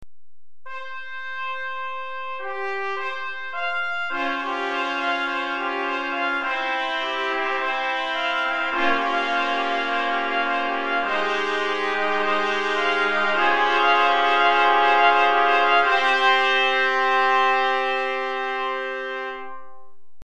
4 Trompettes en Sib